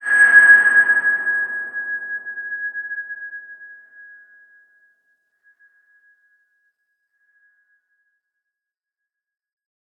X_BasicBells-G#4-pp.wav